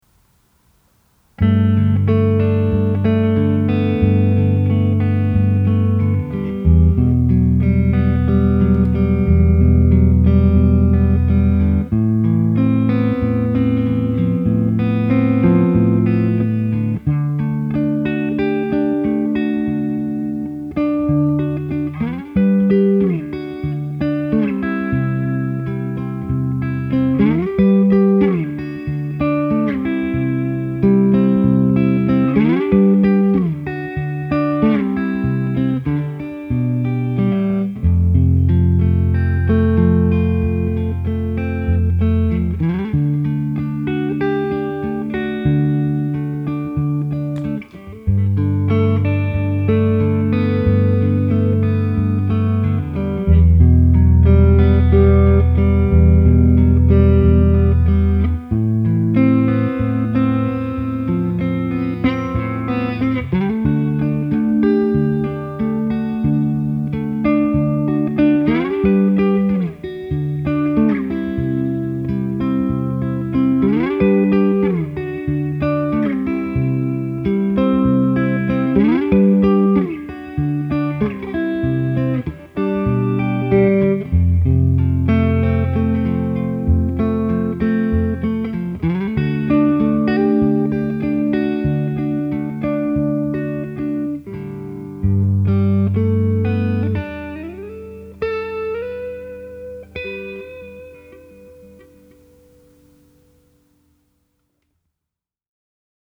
Subtle/Clean
The first clip has me playing clean fingerstyle with a short song. I do the song first without the pedal engaged, then play the song over with the pedal engaged, just adding some subtle chorus.
corona_subtle.mp3